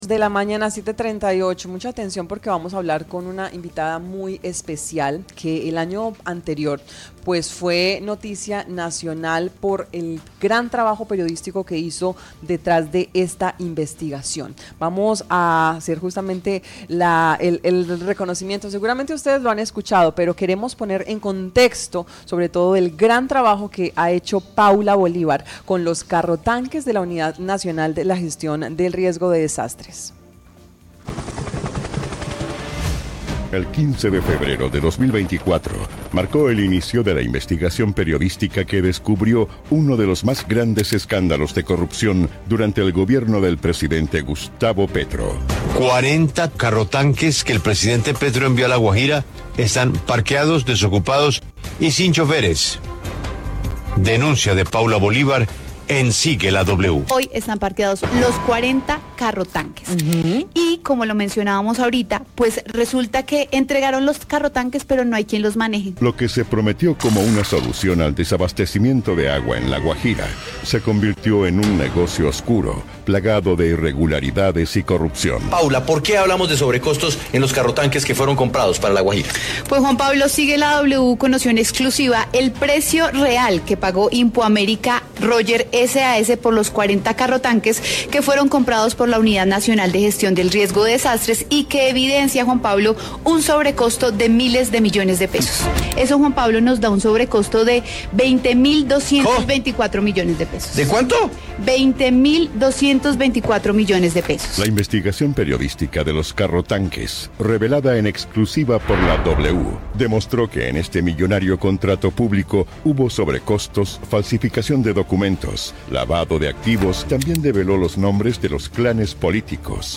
En entrevista con Marandua Noticias